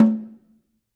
Snare2-HitNS_v5_rr1_Sum.wav